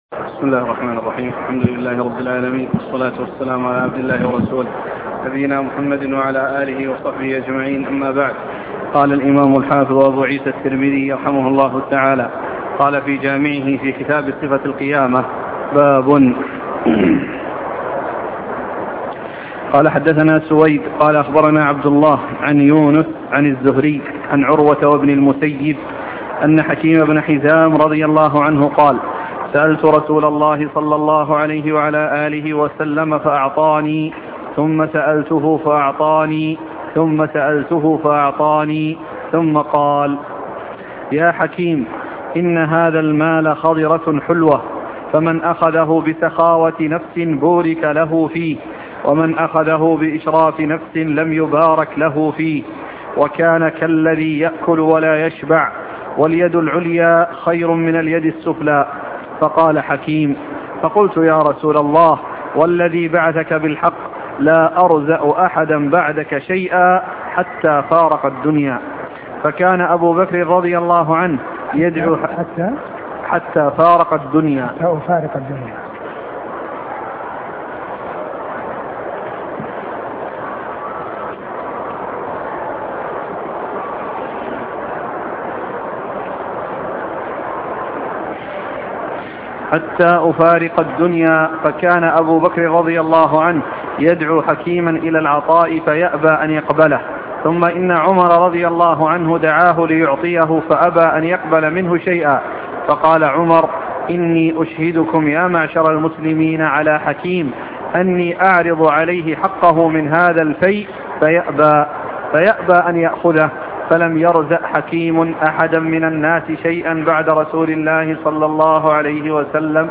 سنن الترمذي شرح الشيخ عبد المحسن بن حمد العباد الدرس 268